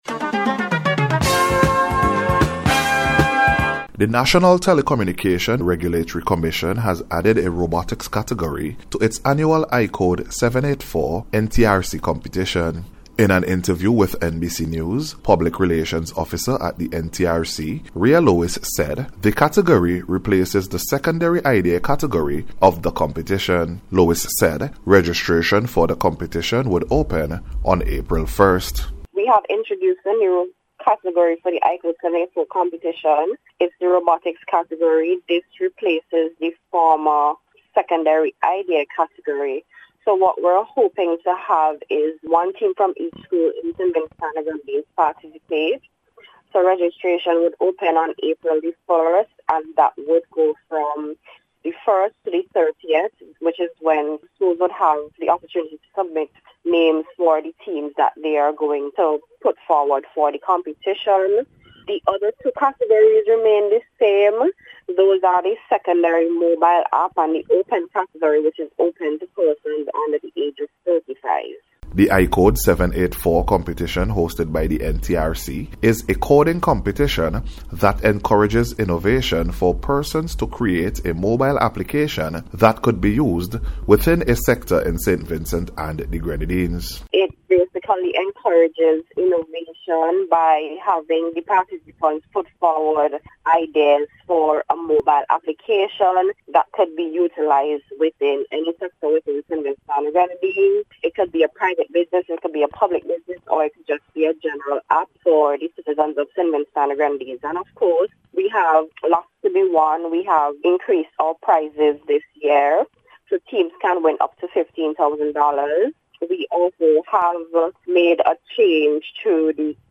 NBC Radio’s Special Report -Friday March 28th 2025